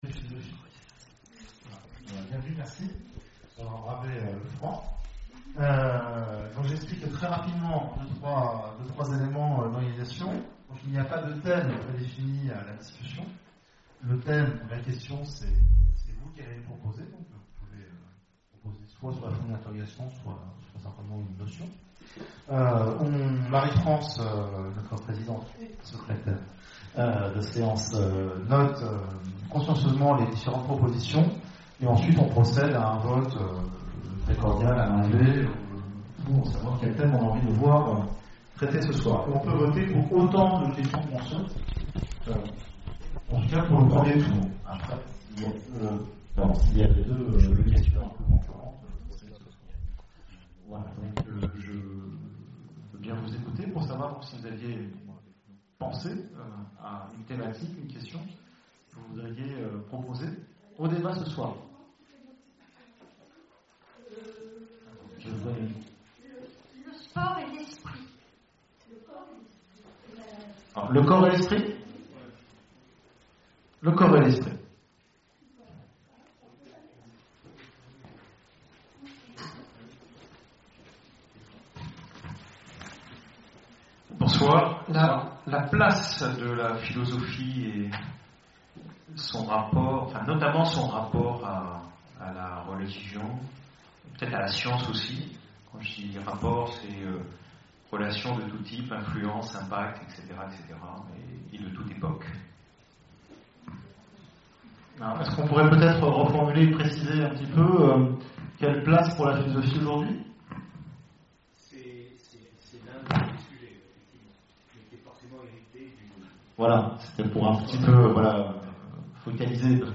Conférences et cafés-philo, Orléans
CAFÉ-PHILO PHILOMANIA Quelle place pour la philosophie aujourd’hui ?